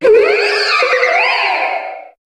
Cri de Méga-Lockpin dans Pokémon HOME.
Cri_0428_Méga_HOME.ogg